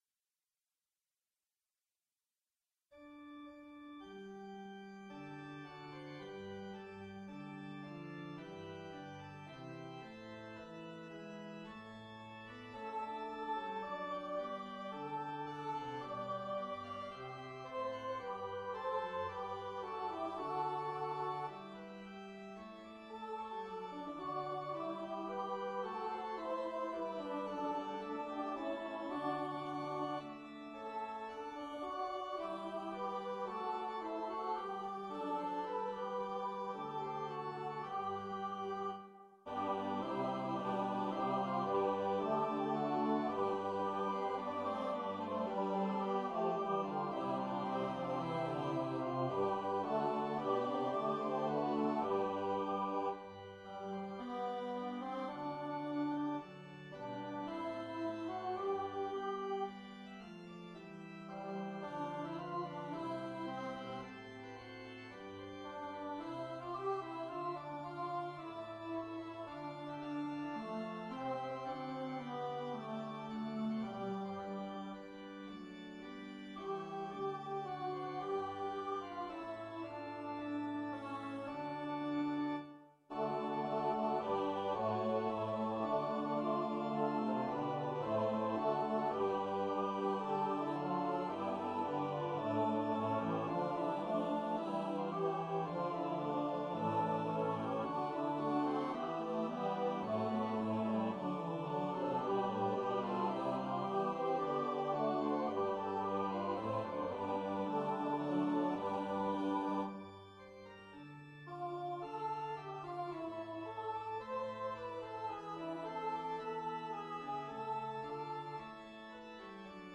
EARLY-SEVENTEENTH-CENTURY ENGLISH SACRED MUSIC
Voices:SSA soli, SAATB chorus & organ